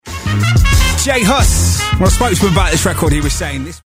読み方
ジェイ・ハス
BBC Radio 1 Extra Mista Jamの読み